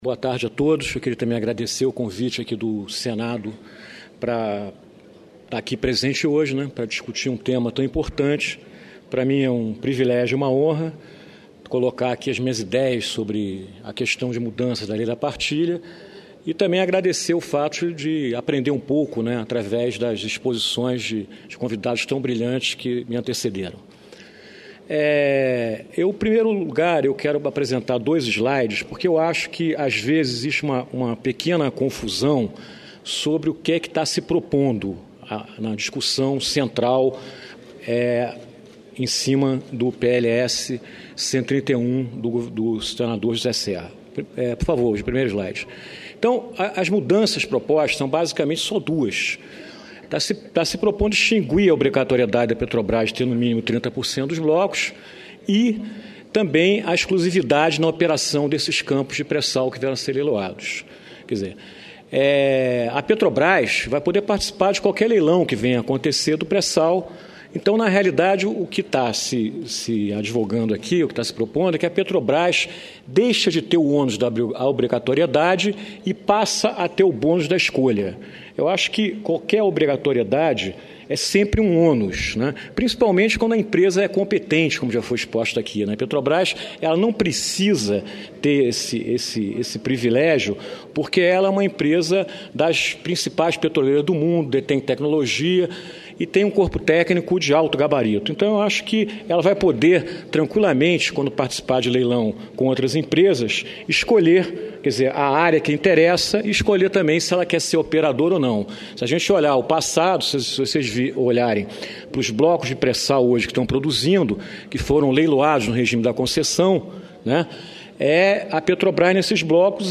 Tópicos: Pronunciamento